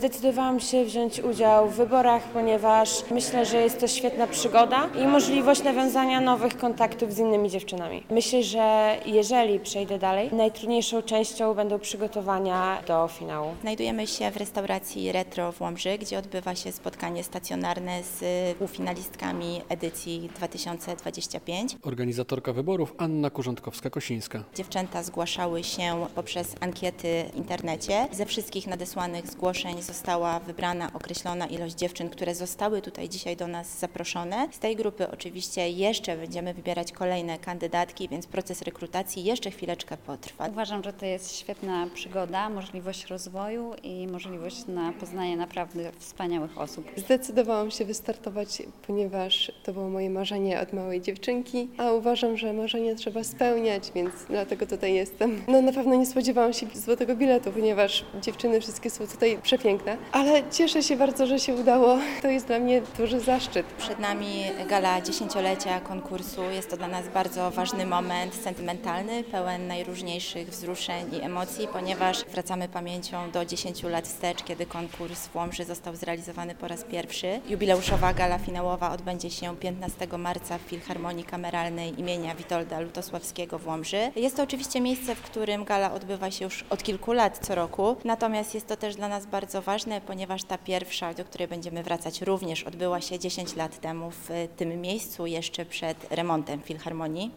Casting do wyborów Miss Polonia Województwa Podlaskiego 2025 w Łomży - relacja